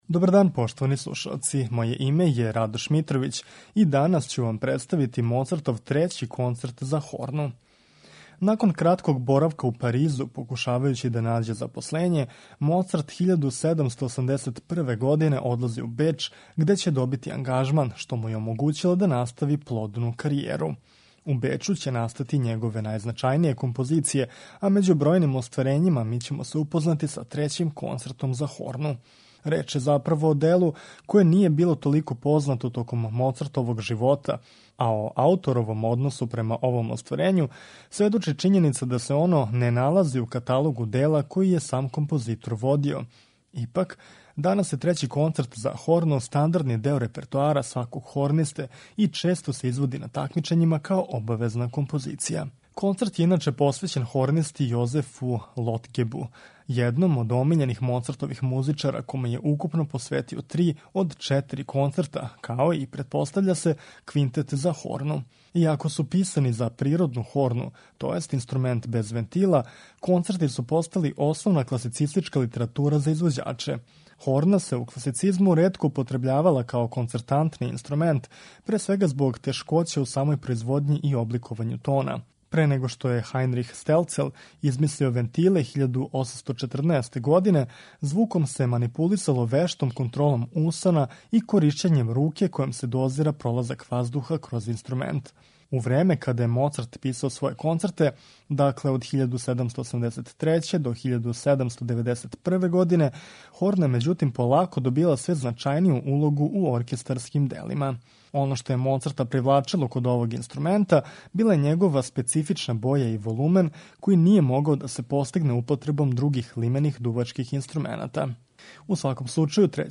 Моцарт - Концерт за хорну